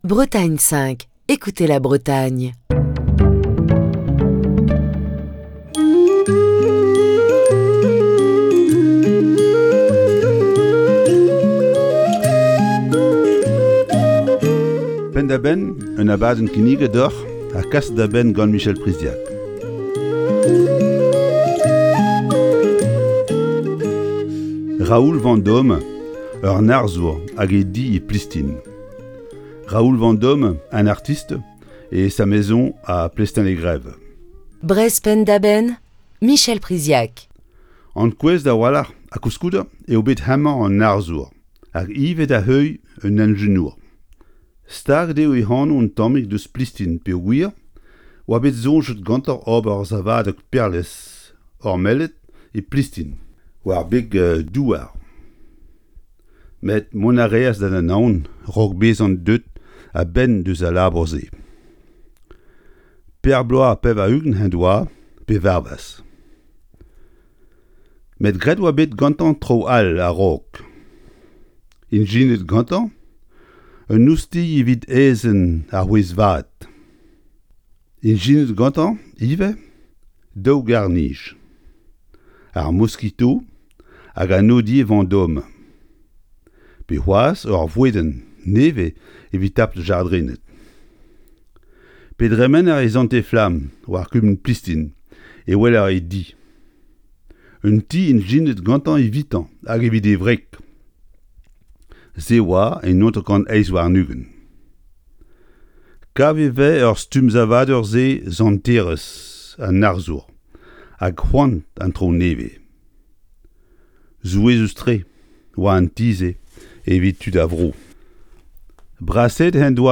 Chronique du 19 novembre 2021.